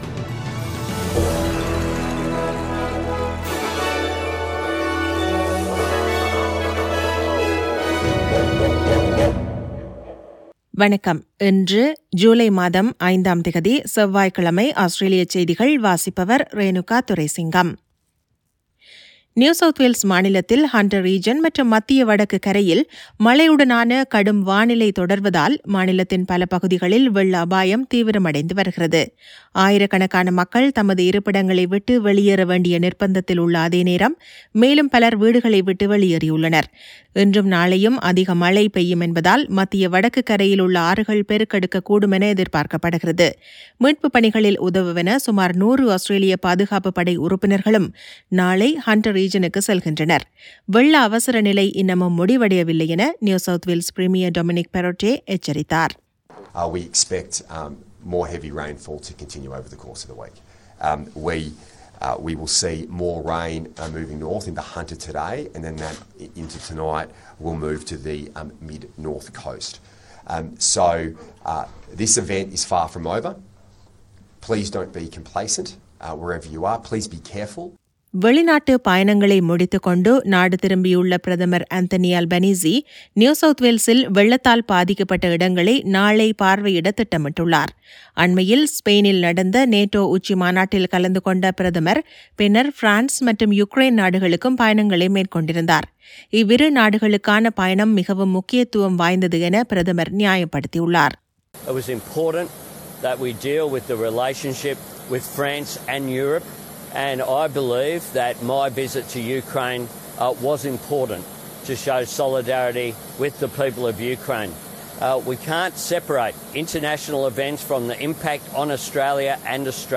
Australian news bulletin for Tuesday 05 July 2022.